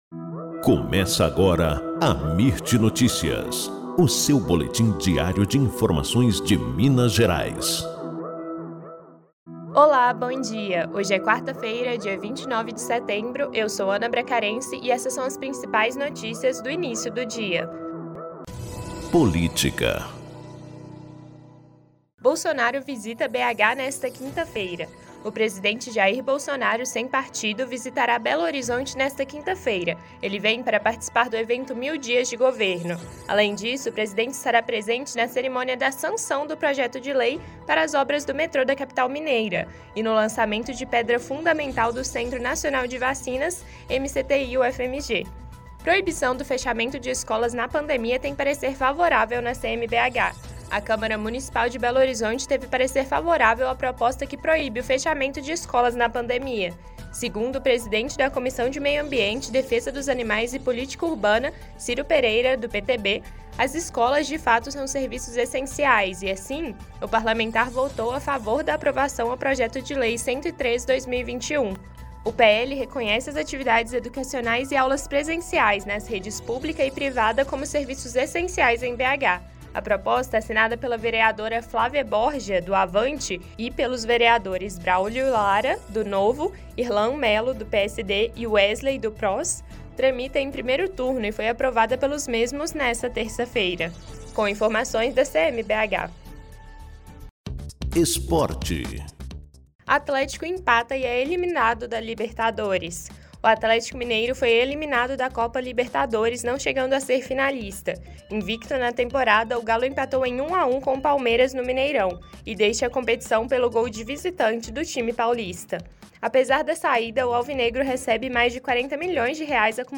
Boletim Amirt Notícias – 29 de setembro